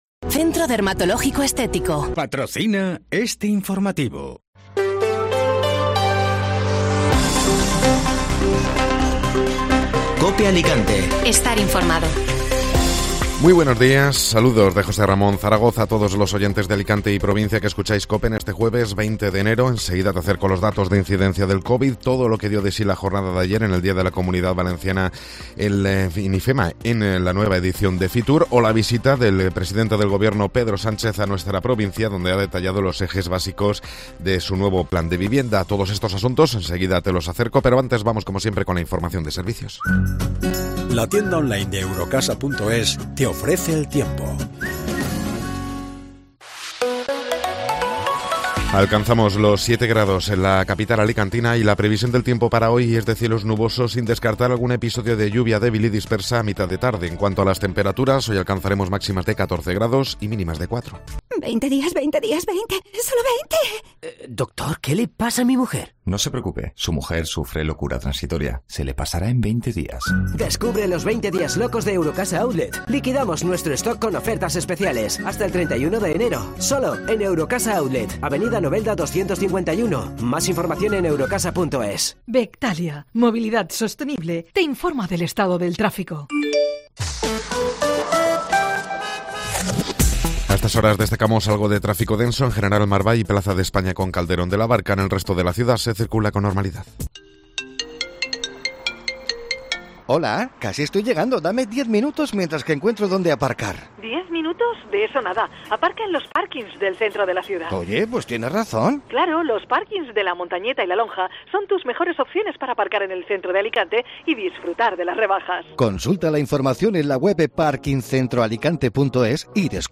Informativo Matinal (Jueves 20 de Enero)